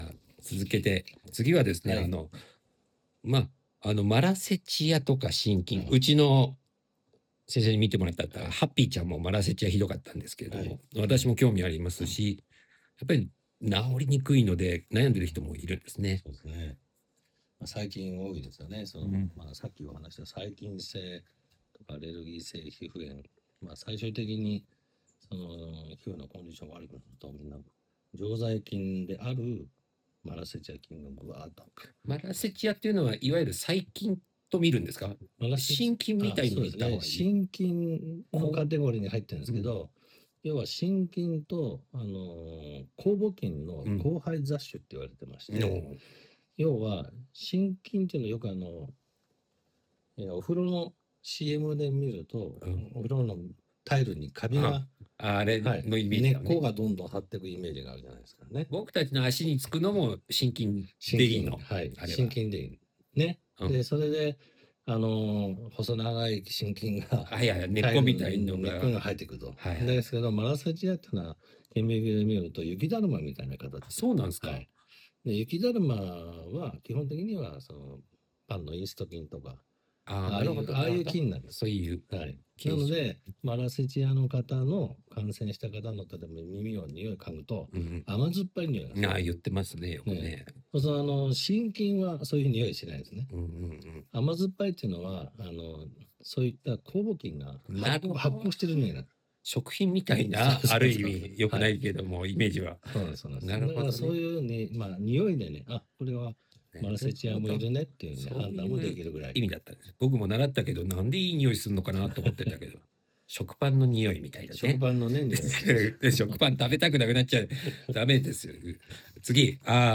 【皮膚ケア・対談音声つき②】マラセチア皮膚炎とシャンプーの関係
※際どすぎる部分を一部カットしております。